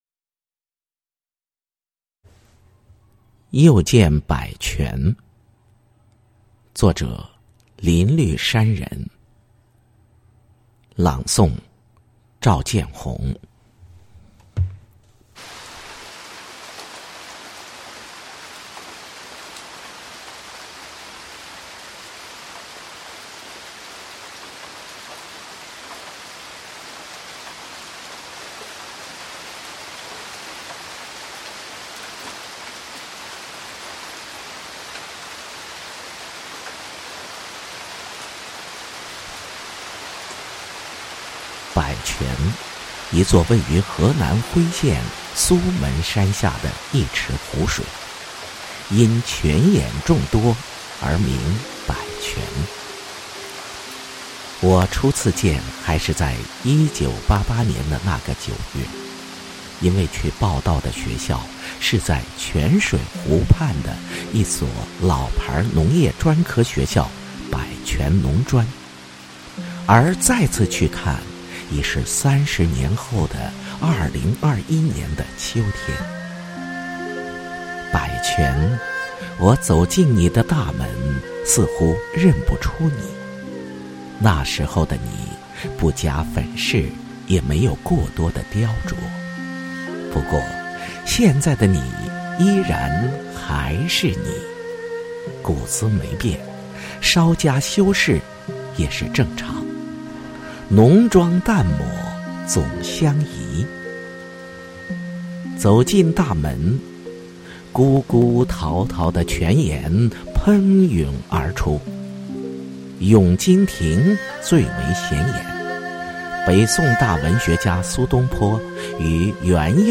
配乐散文：《又见百泉》 | 中国科普作家网